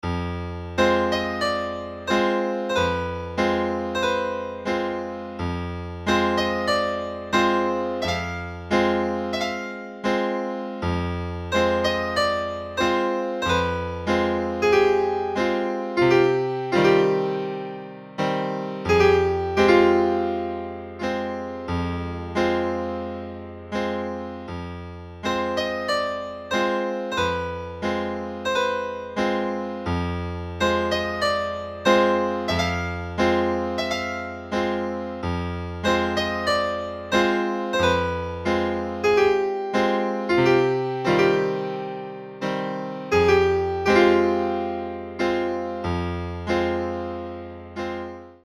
神秘的で非日常的な響きを持っています。
クラシック